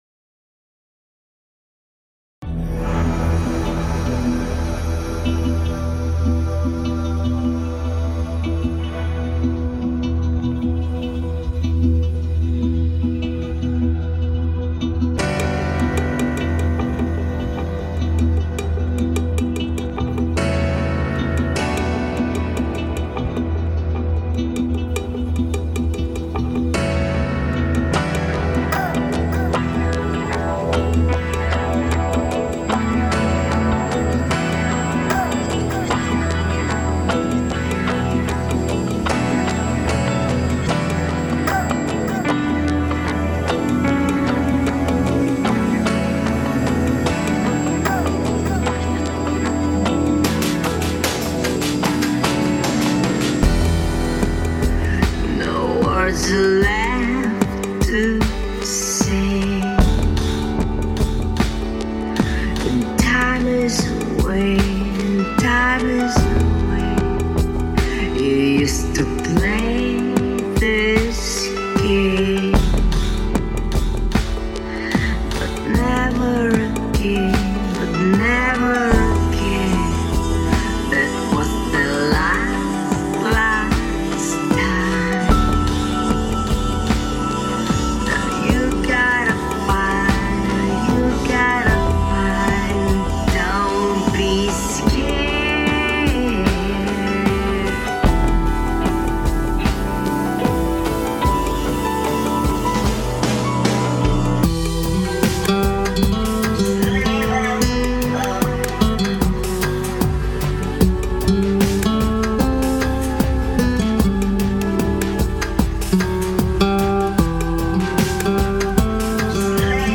mp3,5402k] Рок